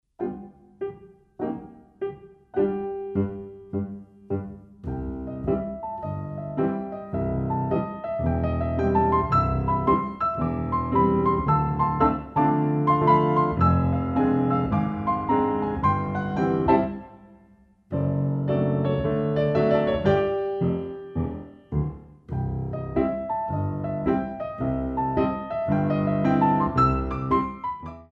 Compositions for Ballet Class
Dégagés à terre